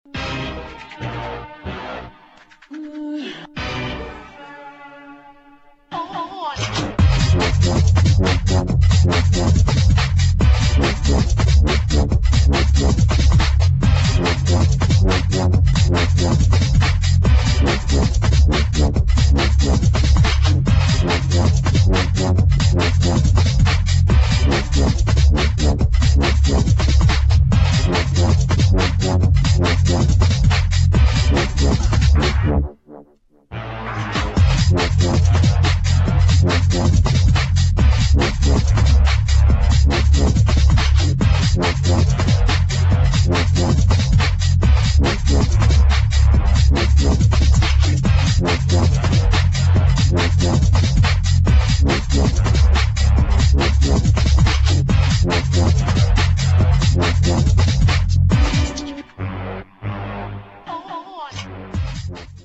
[ DUBSTEP / UK GARAGE / BREAKS ]